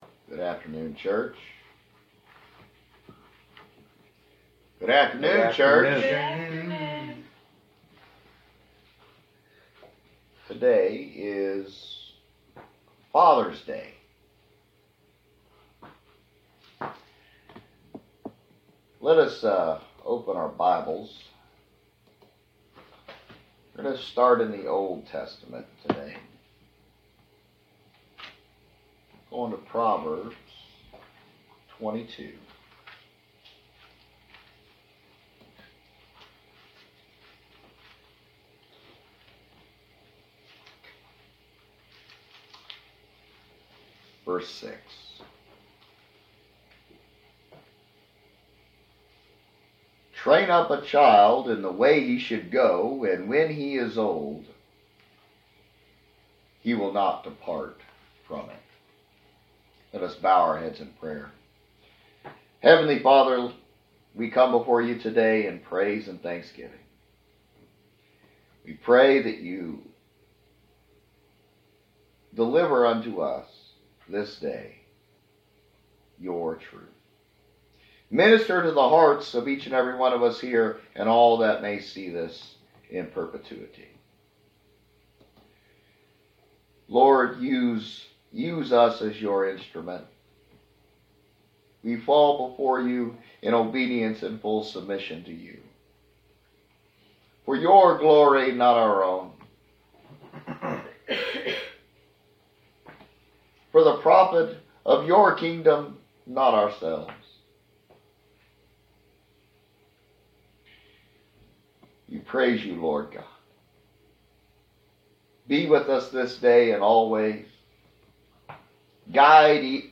Fathers Day 2022 Sermon